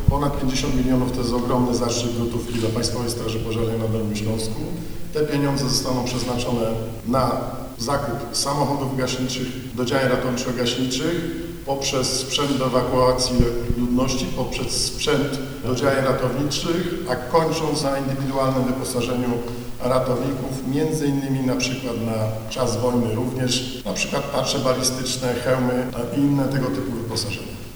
Na co przeznaczy swoją część środków? Mówi nadbryg. Marek Hajduk, dolnośląski komendant wojewódzki PSP.